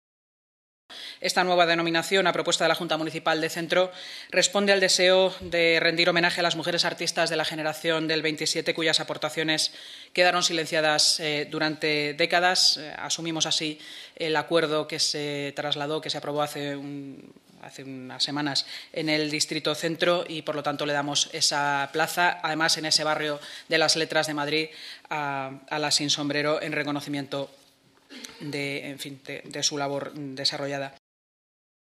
Nueva ventana:Así lo ha contado la portavoz municipal, Inmaculada Sanz, tras l a celebración de la Junta de Gobierno: